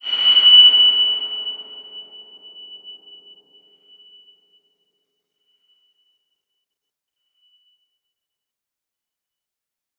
X_BasicBells-F#5-pp.wav